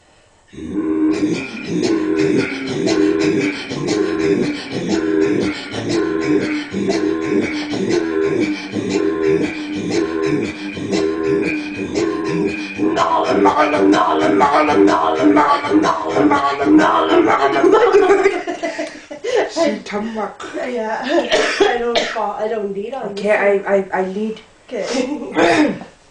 Directly below is an out-take of Inuit throat-singers